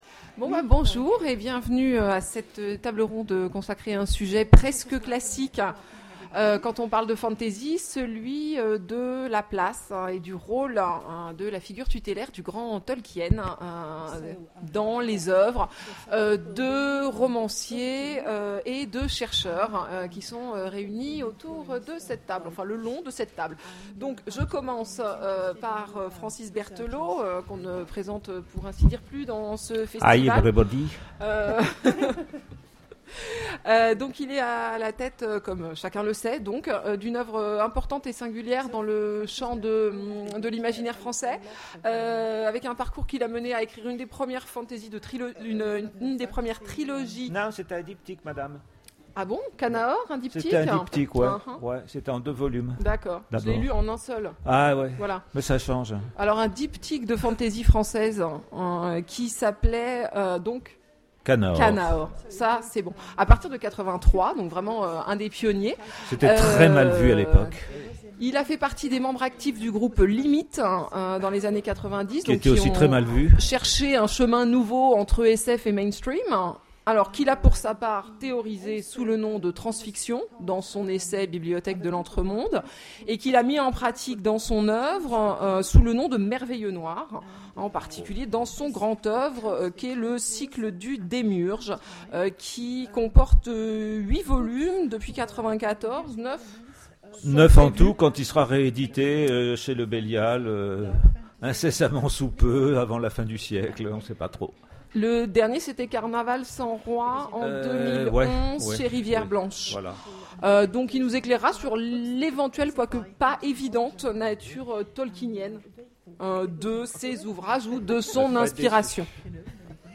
Imaginales 2013 : Conférence Tolkien, l'incontournable...